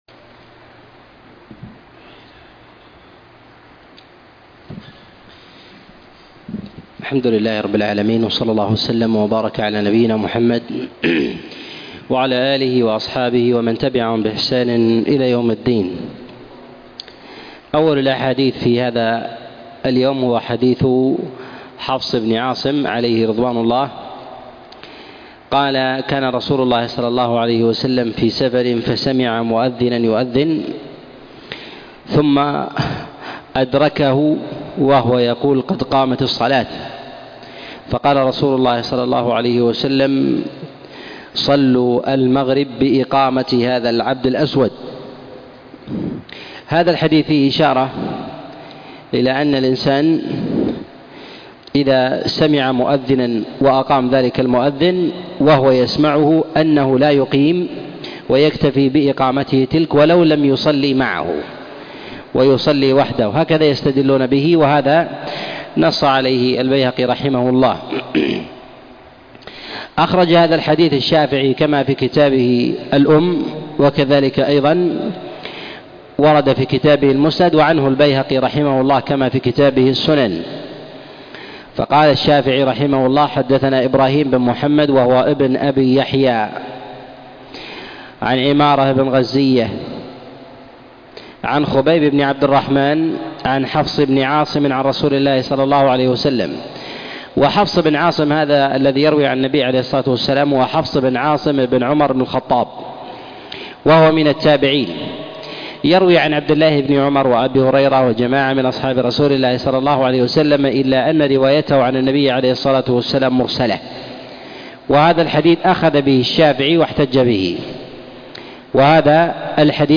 الأحاديث المعلة في الأذان والإقامة الدرس 6